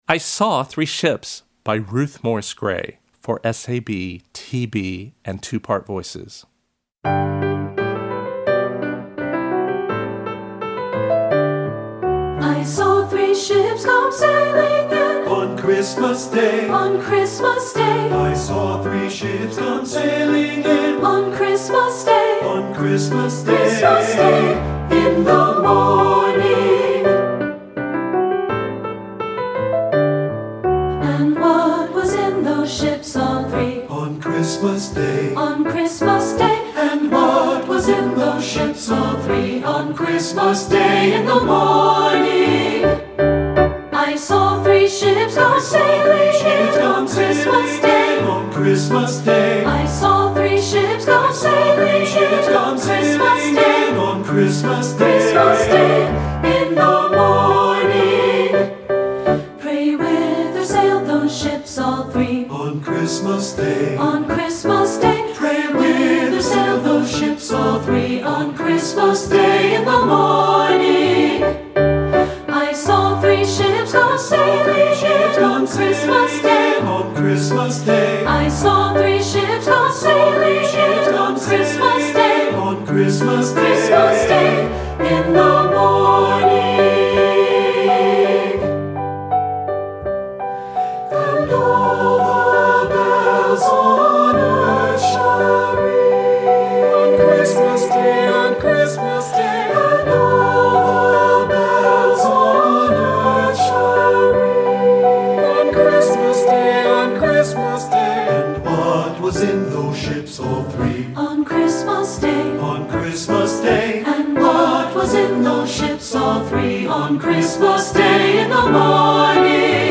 Choral Christmas/Hanukkah
English Carol
SAB